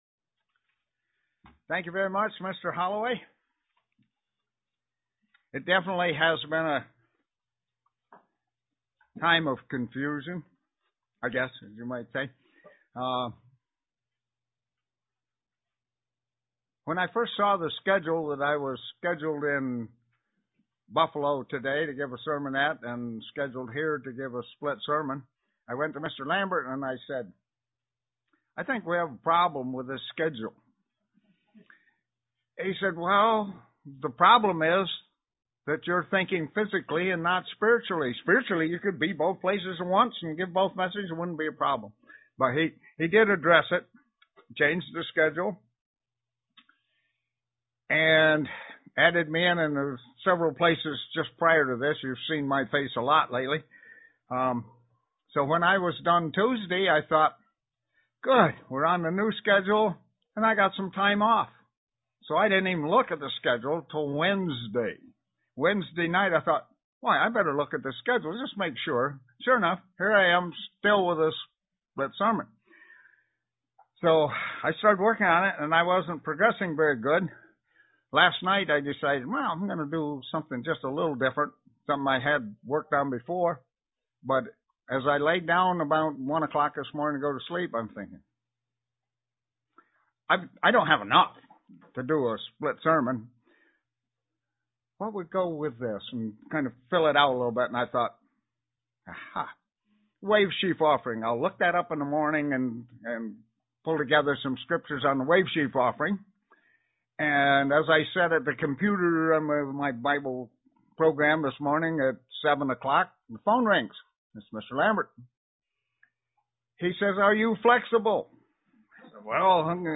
Print Meaning and explanation of the wavesheaf and its relation to the crucifixion and ressurrection UCG Sermon Studying the bible?
Given in Elmira, NY